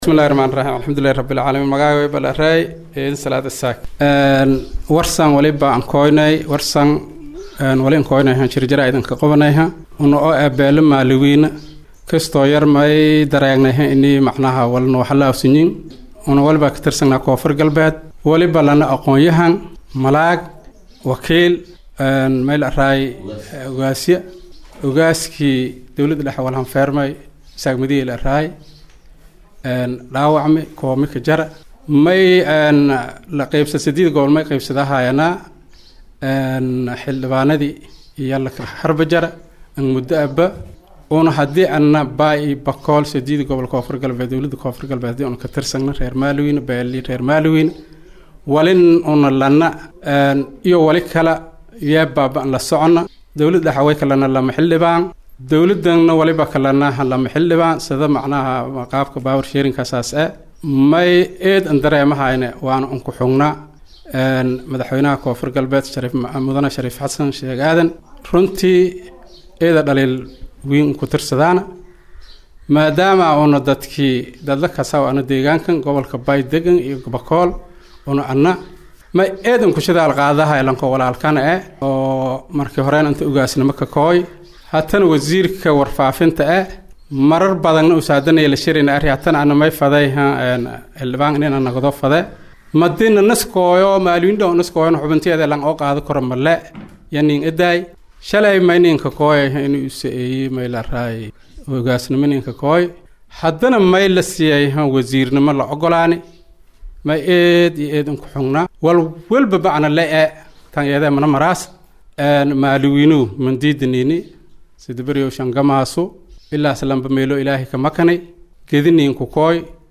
Baydhabo(INO)- Waxgaradka Beesha Macalin Weyne oo Shir Jaraa’id ku qabteen Magaalada Baydhabo ee Xarunta Gobolka Bay ayaa sheegeen in wax weyn ay ka Tabanayaan Madaxweynaha Dowlada Koonfur Galbeed Soomaaliya Sharif Xasan Sheekh Aaden, isla markaana waxay eedayeen Wasiirka Wasaarada War faafinta iyo Wacyi-galinta Bulshada Ugaas Xasan Cabdi Maxamed oo ay sheegeen in uu wax weliba oo jago ah oo leh Beeeshaasi isla doonayo inuu qabto.